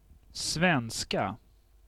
Ääntäminen
Ääntäminen : IPA: [ˈsvɛnsˌka] Haettu sana löytyi näillä lähdekielillä: ruotsi Käännös Ääninäyte Erisnimet 1.